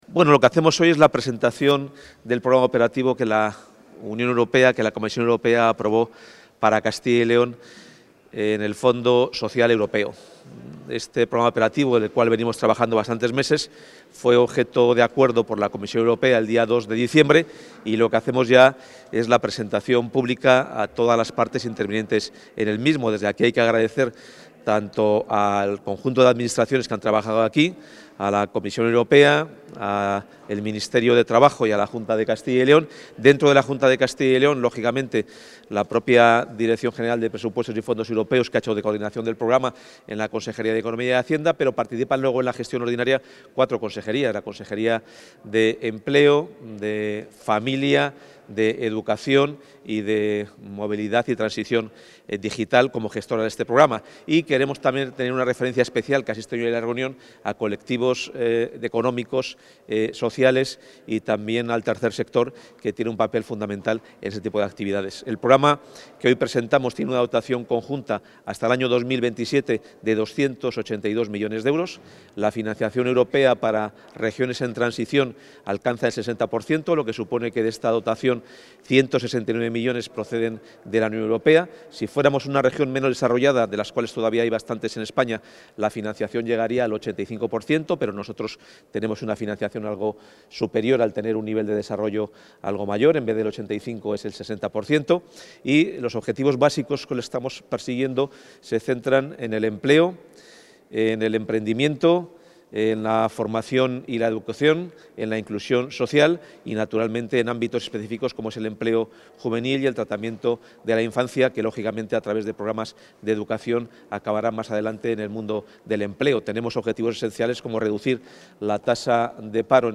Intervención del consejero.
El consejero de Economía y Hacienda, Carlos Fernández Carriedo, ha presentado en un acto con representantes de entidades sociales –de inclusión social y discapacidad–, con agentes económicos y sociales, universidades y entidades locales, entre otros, el programa del Fondo Social Europeo+ de Castilla y León para el periodo 2021-2027.